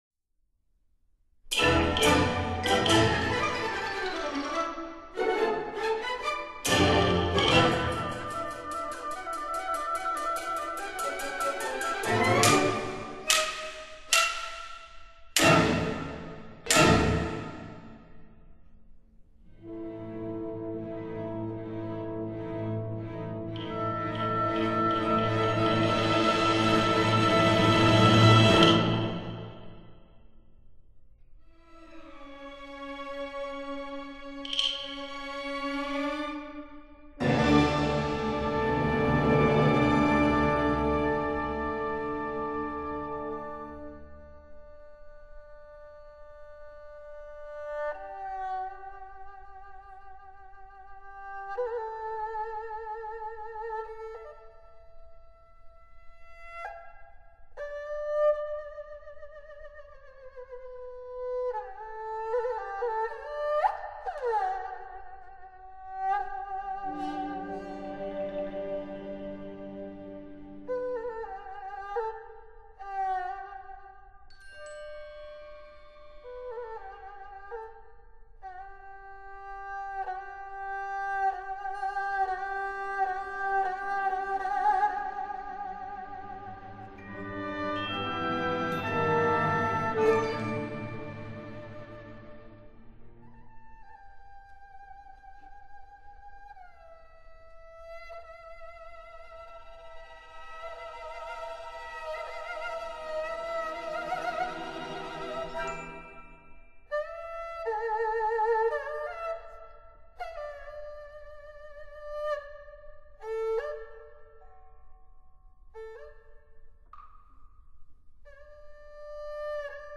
录音地点：北京世纪剧院 Beijing Century Theatre